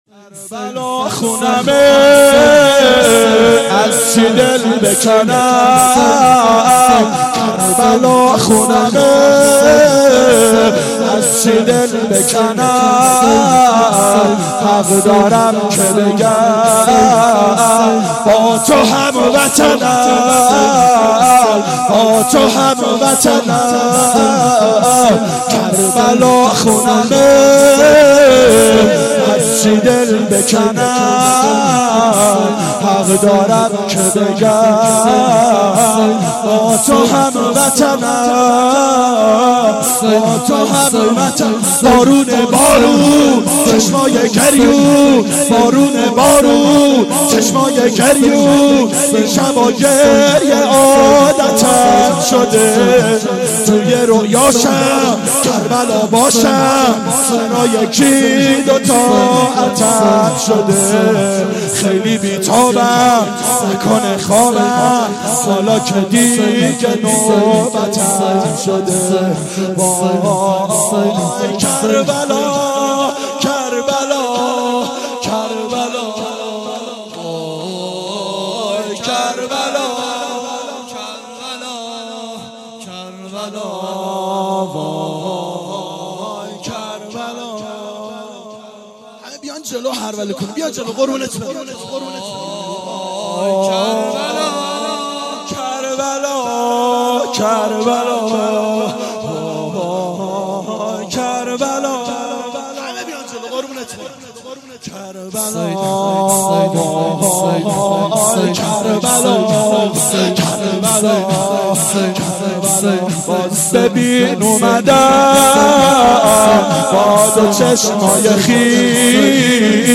فاطمیه اول 92 هیأت عاشقان اباالفضل علیه السلام منارجنبان